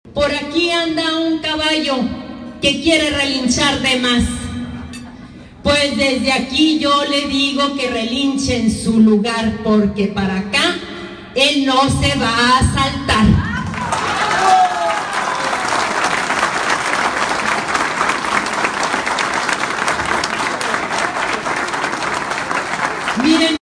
Parral.- La candidata a la dirigencia estatal de acción nacional, Rocío Reza, encabezó una reunión con militancia de Parral, a quienes les dirigió un discurso donde atacó a los malos gobiernos y anunció que el PAN volverá a ser opción en el estado rumbo al 2021.